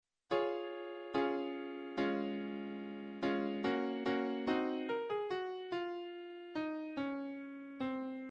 ２０６小節目からは美しい新たな主題とも呼べるものがDes　durであらわれる。